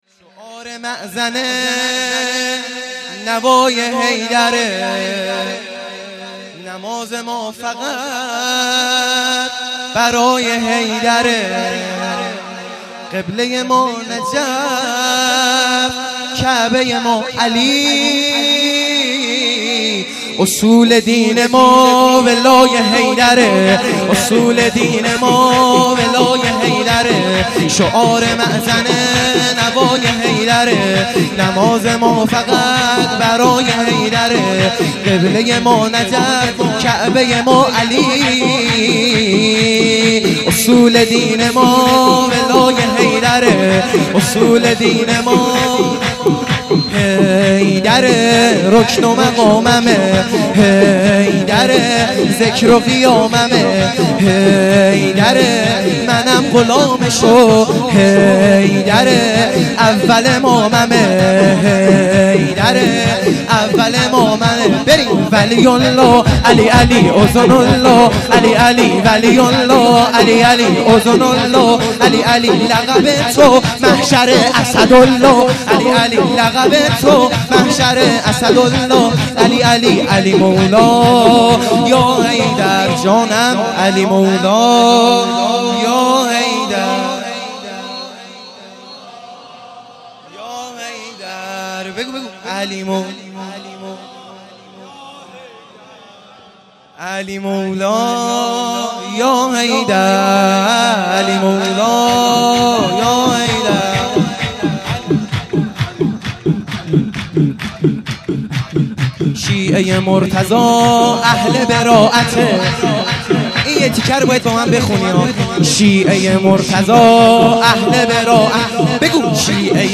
0 0 سرود